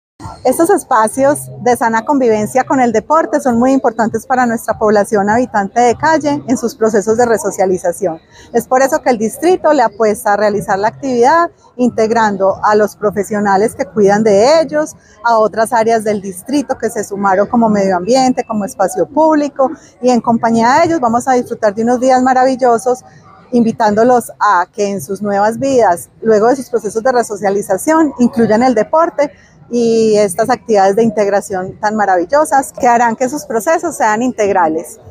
Palabras de Clara Vélez, subsecretaria de Grupos Poblacionales